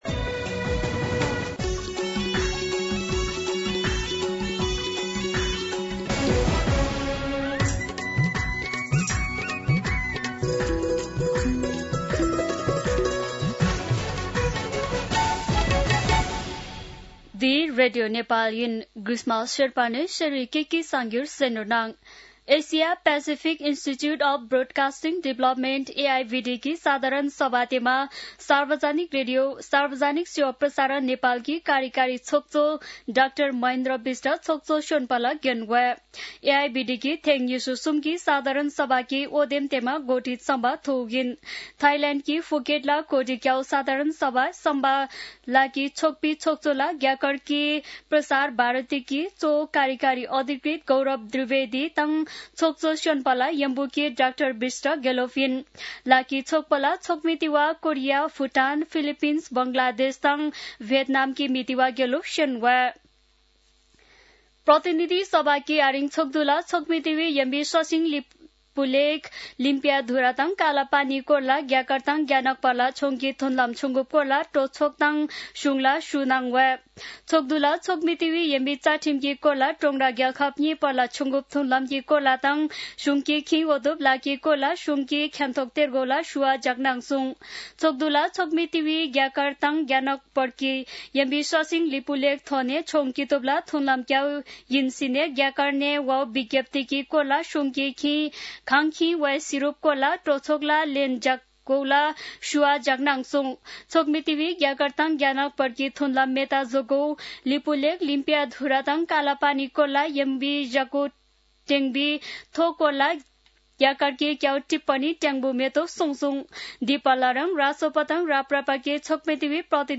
An online outlet of Nepal's national radio broadcaster
शेर्पा भाषाको समाचार : ५ भदौ , २०८२